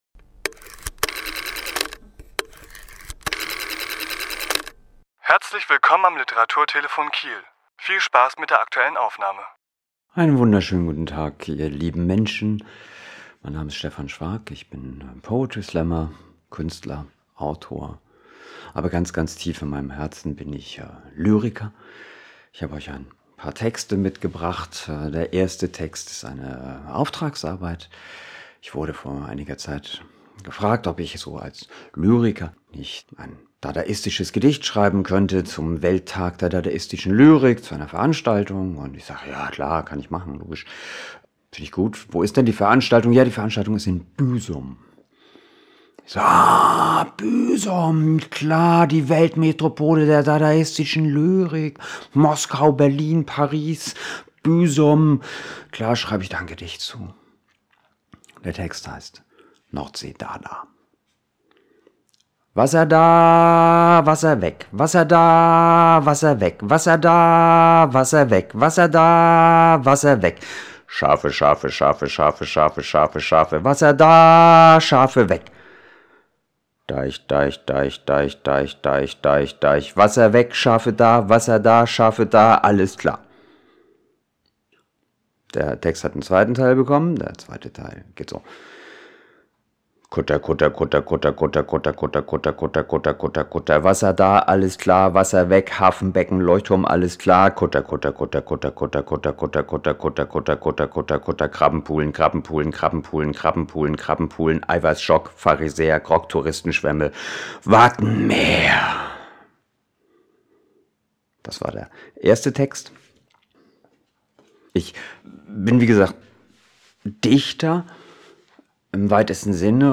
Autor*innen lesen aus ihren Werken